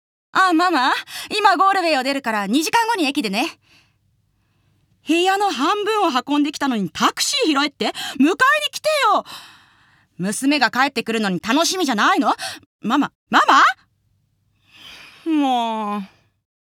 ボイスサンプル
台詞7